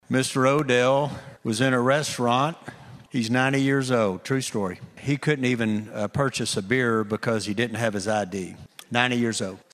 CLICK HERE to listen to details from State Senator Darrell Weaver.
A measure to allow clerks and restaurant servers to not ask for identification when selling alcohol advances.  State Senator Darrell Weaver tells a story about a 90-year-old man who was not allowed to purchase a beer because he did not have an ID.